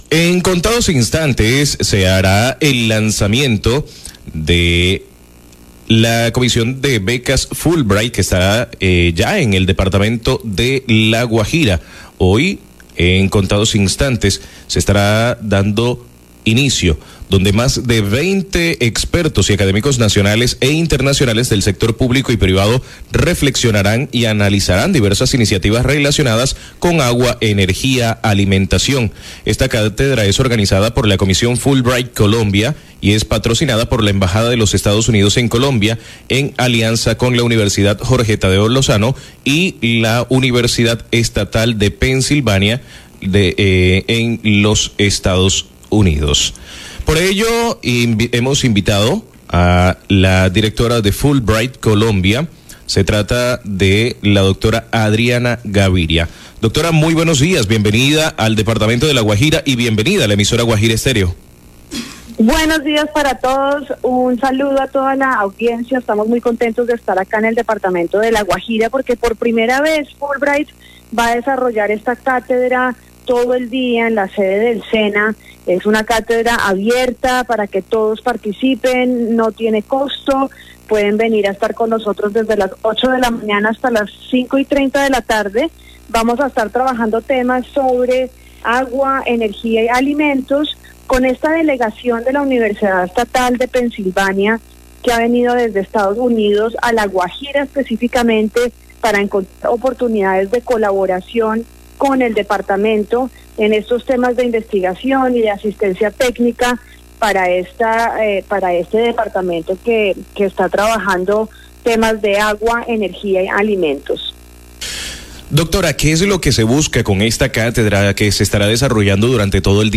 Guajira Stereo entrevistó